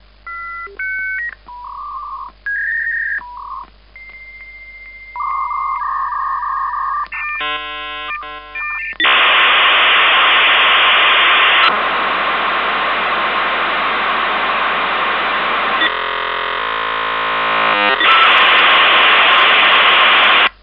Old modem sound, dial-up modem
Old Modem Dial Up Modem 3 Mp 3